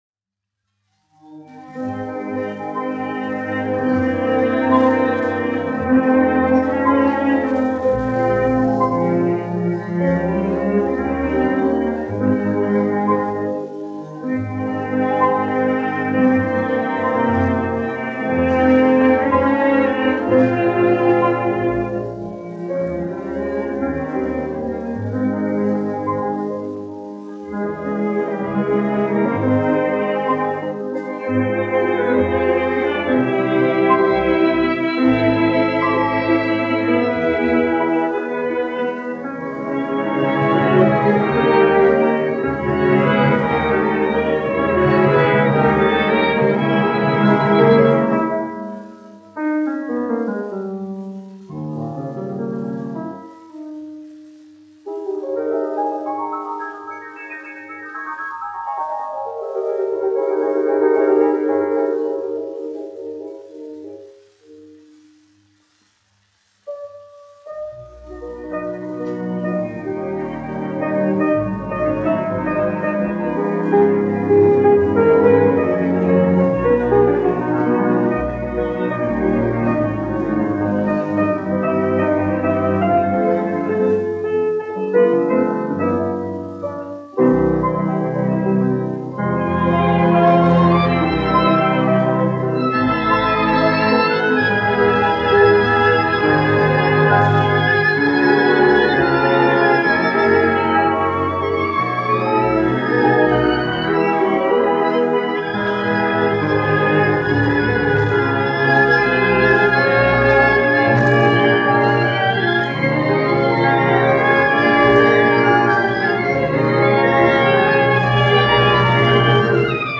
1 skpl. : analogs, 78 apgr/min, mono ; 25 cm
Orķestra mūzika, aranžējumi
Skaņuplate